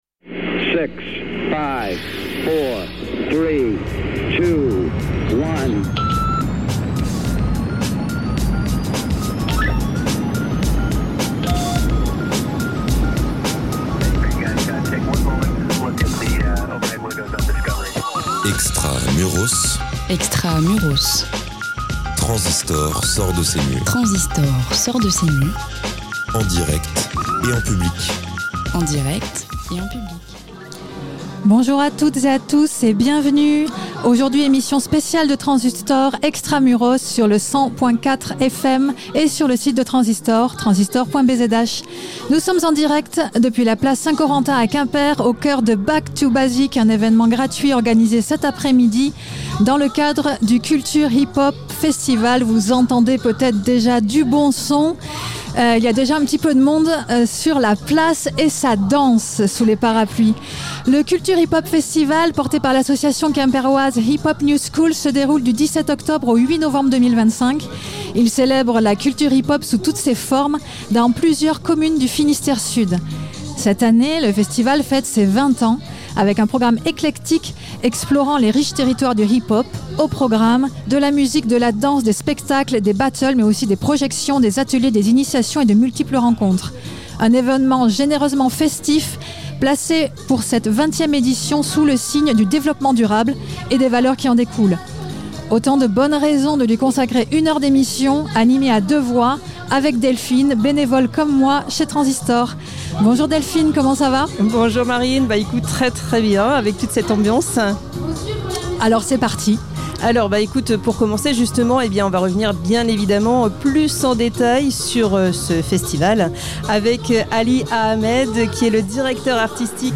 Une émission spéciale de Transistoc'h, en direct de la place Saint Corentin à Quimper, au cœur de Back to Basic, un événement gratuit organisé dans le cadre du Cultures Hip Hop Festival porté par l'association Quimpéroise Hip Hop New School.
Autant de bonnes raisons de lui consacrer une heure d'émission, légèrement écourtée par un excès d'eau tombant du ciel et inondant tables et matériel.